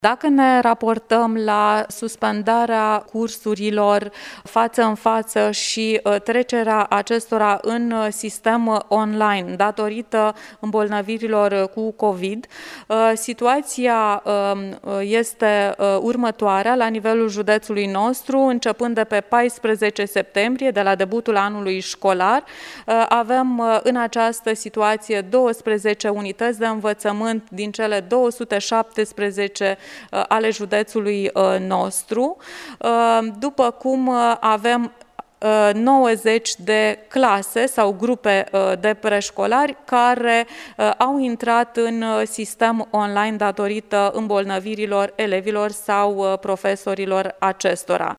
Inspectorul general al Inspectoratului Şcolar Judeţean Iaşi, Genoveva Farcaş a adăugat că la acestea se adaugă 90 de clase care funcţionează în sistemul de predare online: